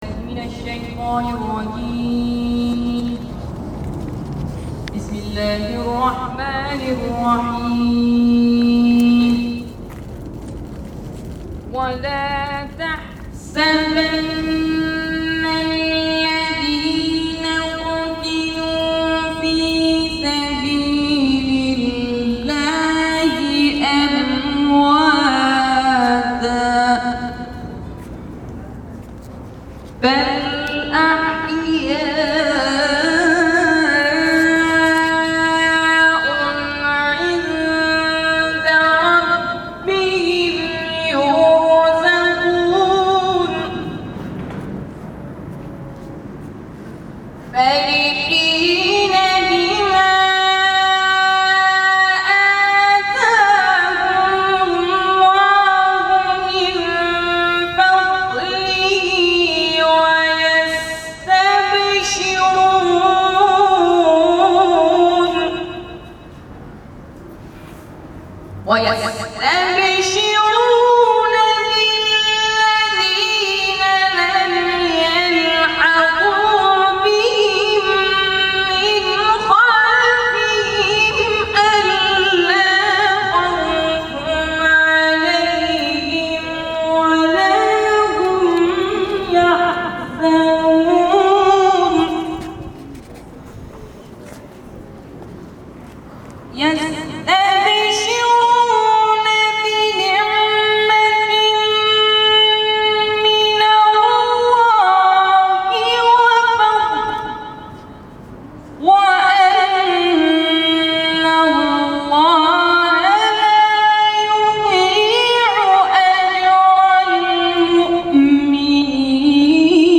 بنابر این گزارش، نوجوانان طرح ملی تلاوت در مسجد امام حسن(ع) جزیره کیش و قبل از شروع تلاوت‌ها طی مراسمی با گلاب به غبارروبی مزار شهدای گمنام مسجد امام حسن(ع) پرداختند و با قرائت فاتحه علاوه بر زنده نگه داشتن یاد و خاطره آنها با این شهدای گمنام مناطق عملیاتی فاو، شلمچه و دجله تجدید میثاق کردند.
در ادامه تلاوت یکی از قاریان نوجوان در این مراسم ارائه می‌شود.